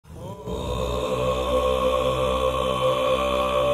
Moyai_Sound_Effect
moyai-sound-effect.mp3